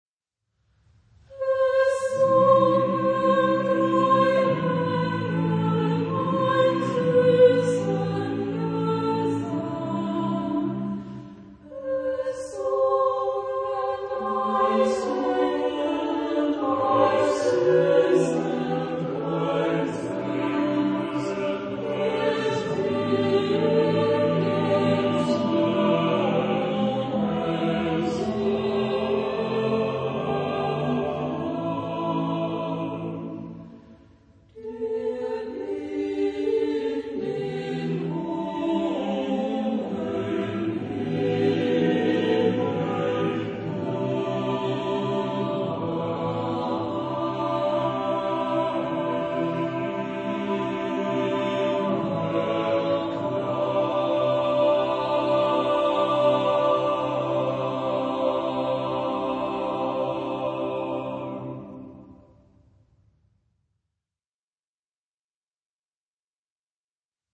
Genre-Style-Form: Folk music ; Madrigal ; Sacred
Type of Choir: SAATBB  (6 mixed voices )
Tonality: C major